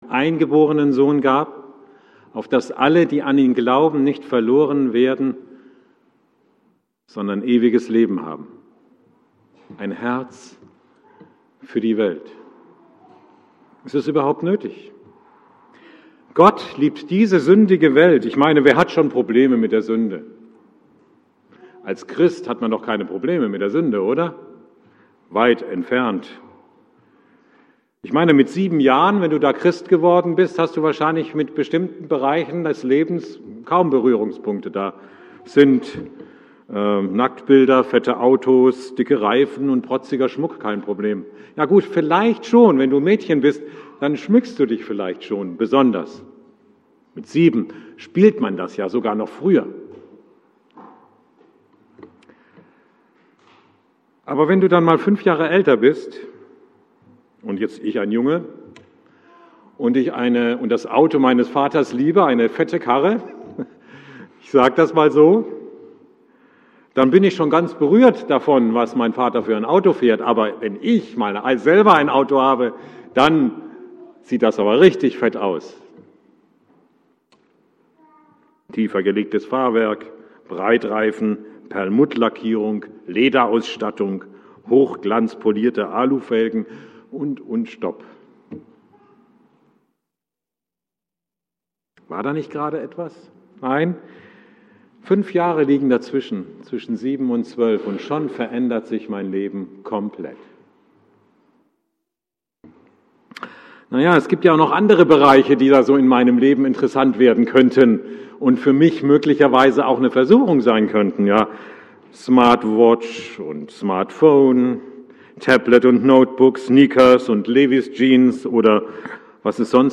Abendmahlspredigt 08.06.19